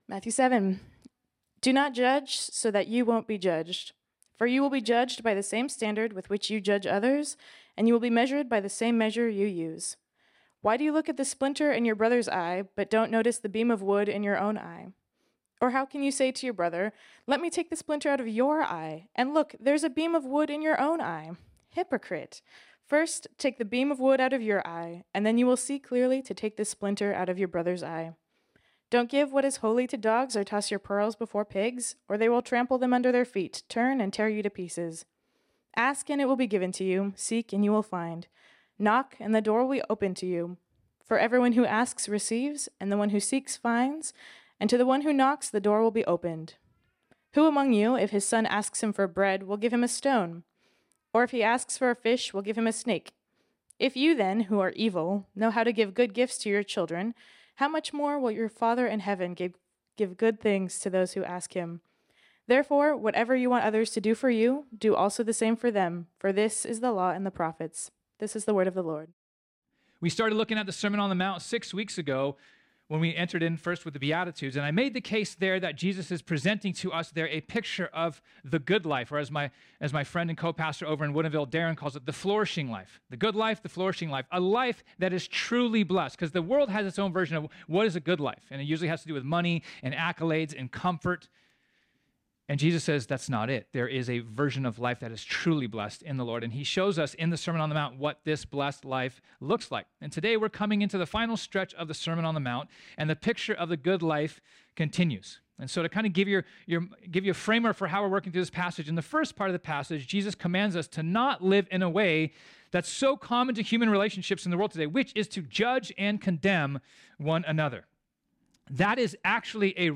This sermon was originally preached on Sunday, March 10, 2024.